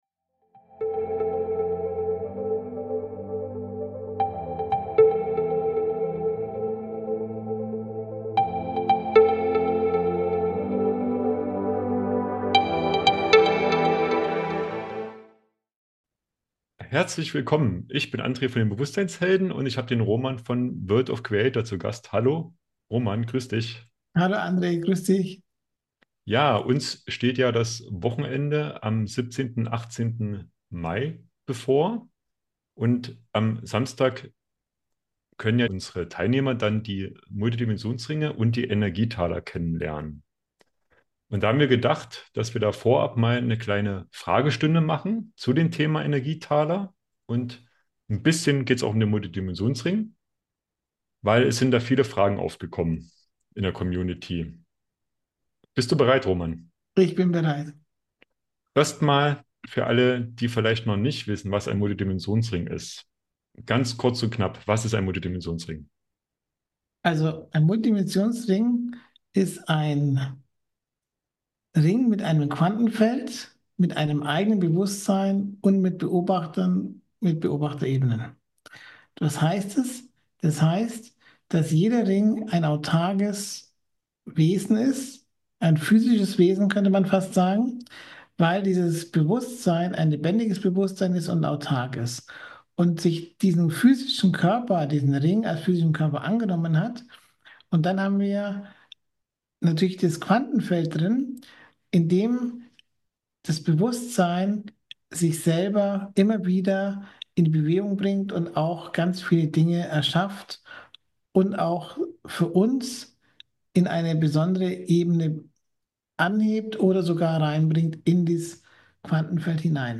Was erwartet euch in diesem Gespräch?...